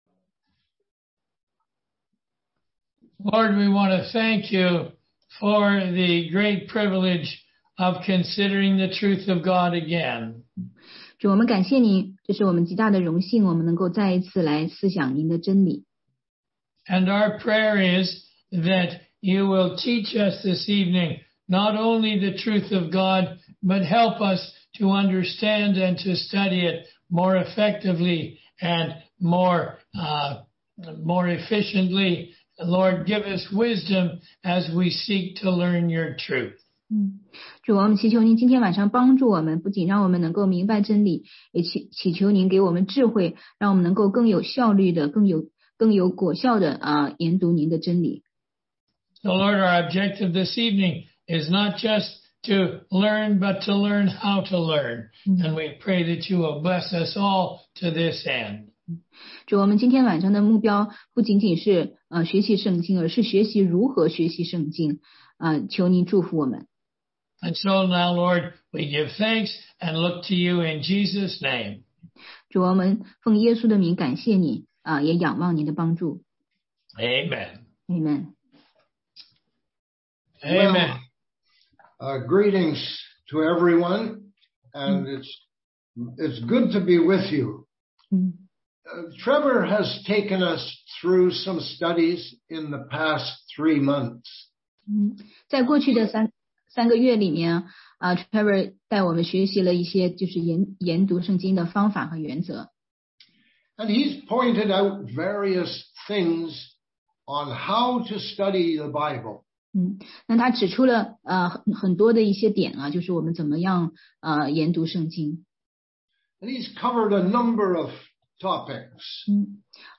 16街讲道录音 - 读经的方法和原则系列之十四：认识作者、正确的态度和默想
中英文查经